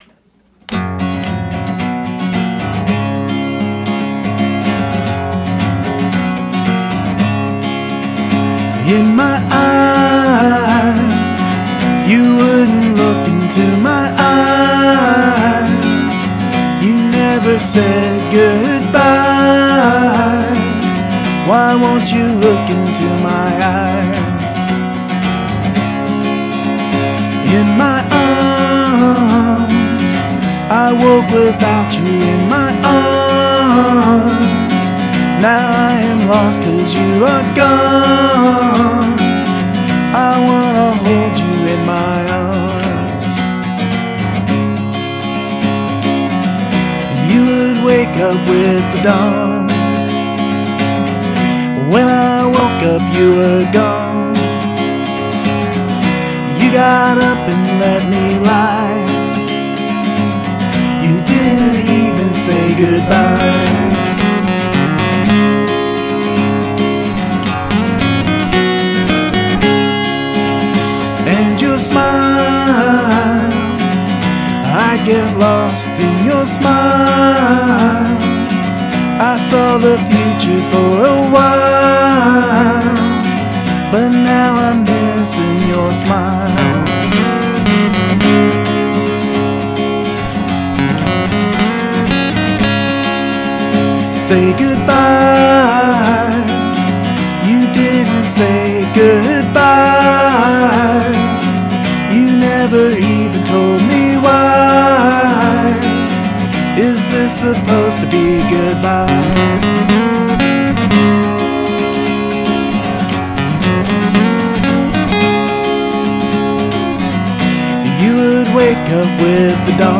One rhythm guitar track
One main vocal track
One track of doubled verse vox
One track of chorus guitar fills
One track of 'leads' during breaks
Recorded via the BR-900CD